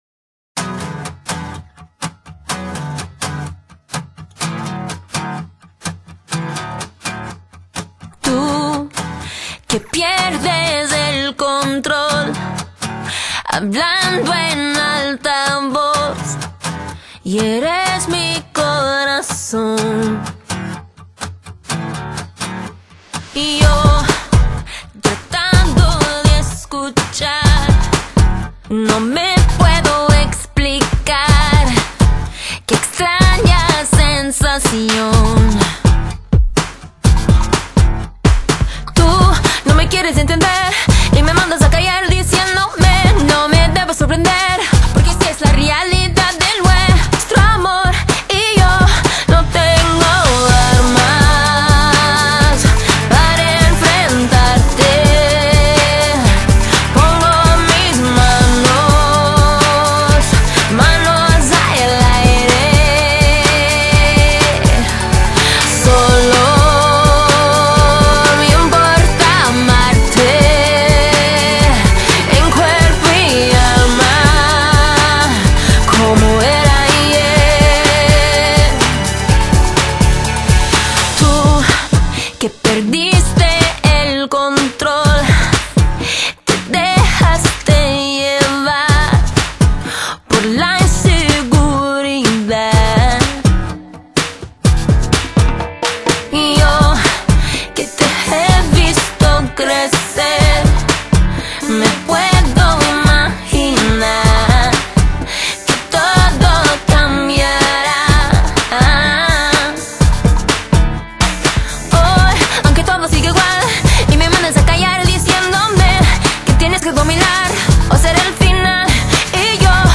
音樂風格︰Latin Pop, Dance Pop | 1CD |